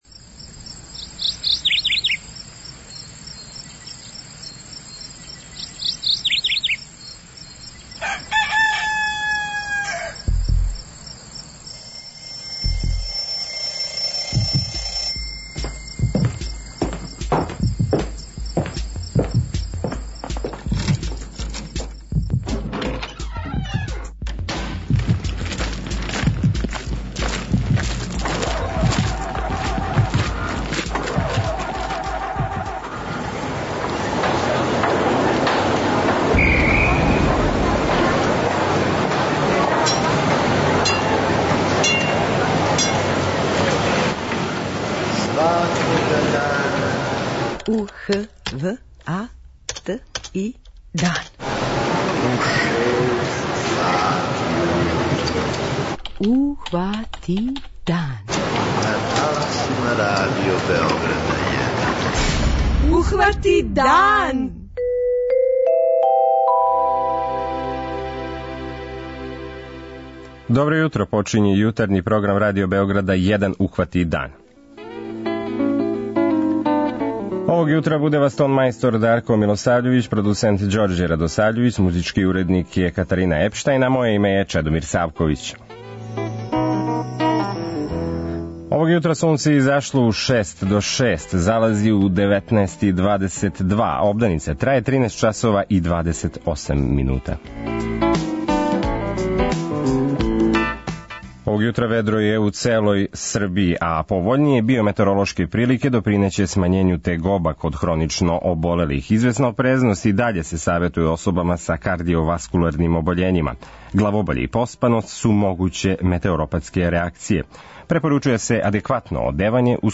Овог јутра вас, наше слушаоце, питамо да ли сте спремни за тај процес и да ли вам је све јасно у вези са дигитализацијом.
преузми : 43.15 MB Ухвати дан Autor: Група аутора Јутарњи програм Радио Београда 1!